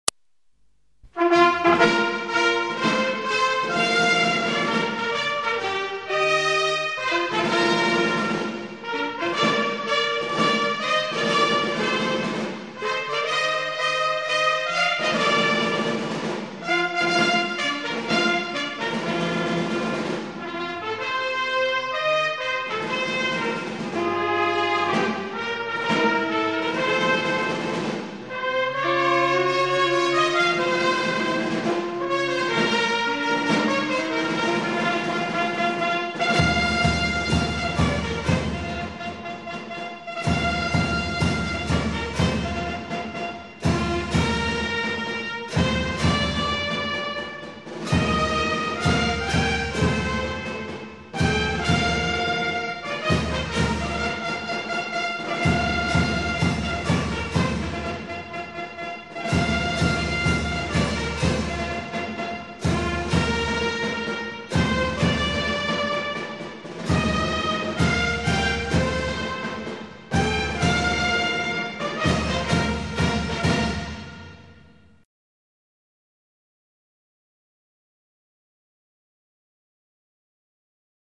Le CD de la Fanfare 1990-1991
Avec pour les chants, la participation de la Garde d'Honneur du Collège.